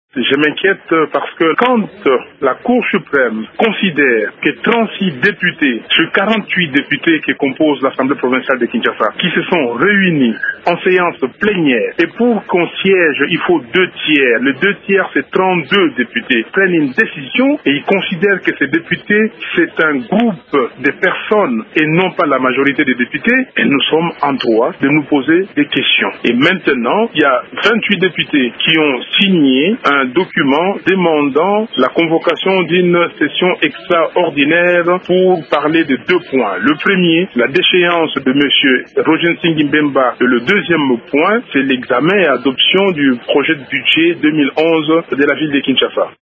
C’est ce qu’a déclaré, mercredi à Radio Okapi, le député provincial Martin Fayulu.
Extrait des propos de Martin Fayulu.
ReactionMartinFAYULU.mp3